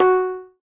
snare.ogg